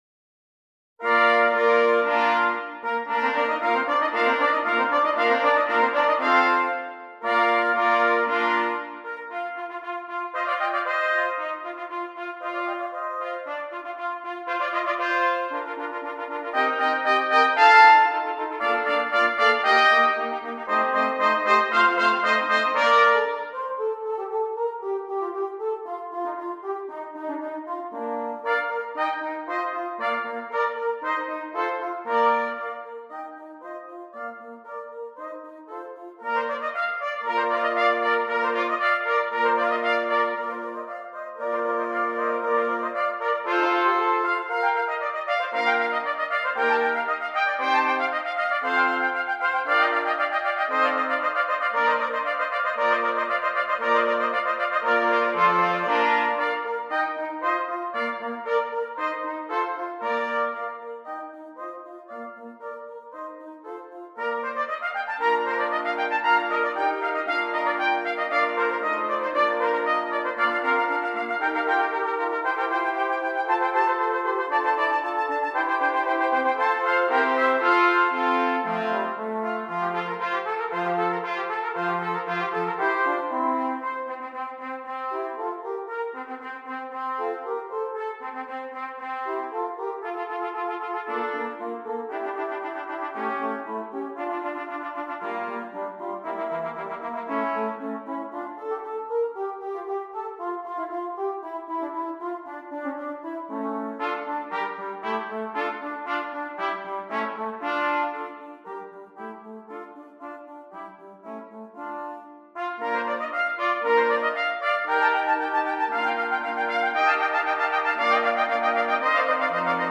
6 Trumpets